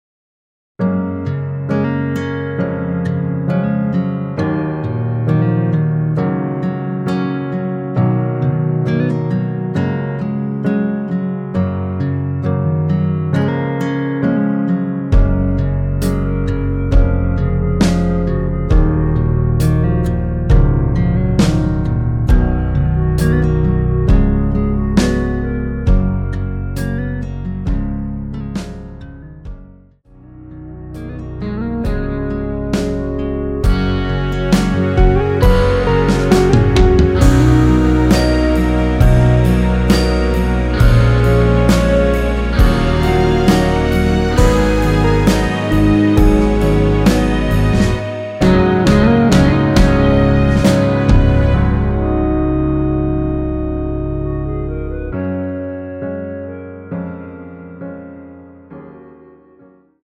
F#
앞부분30초, 뒷부분30초씩 편집해서 올려 드리고 있습니다.
중간에 음이 끈어지고 다시 나오는 이유는